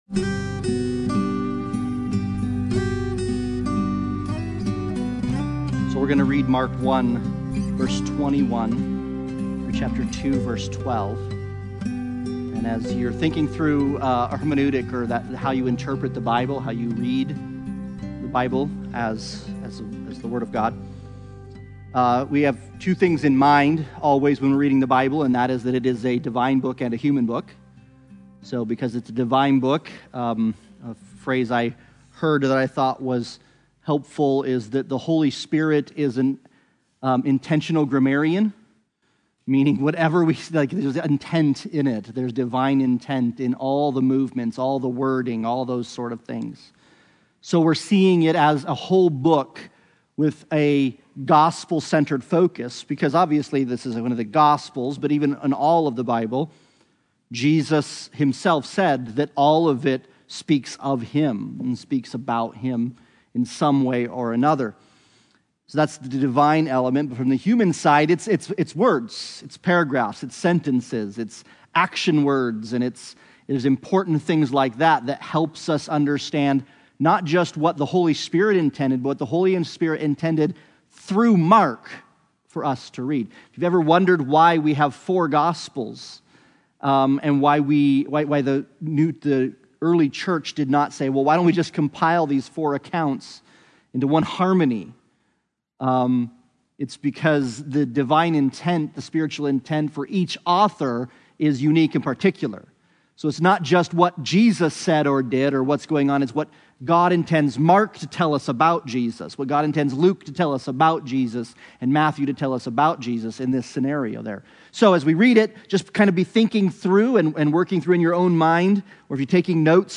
Sunday Bible Study